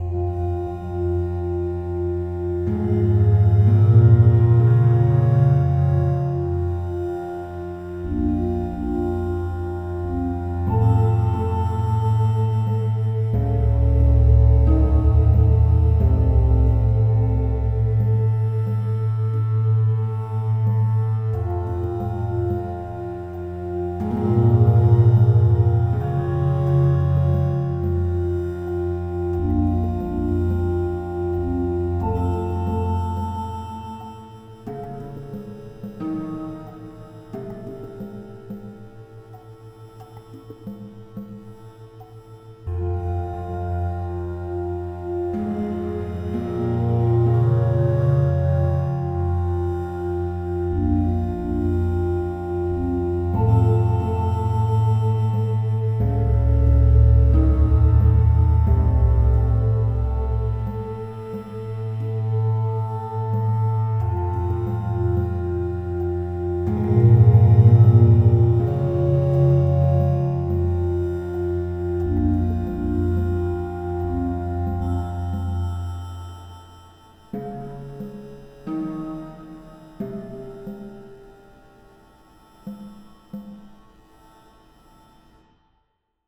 Basic droney ambience with some desert aspect to it.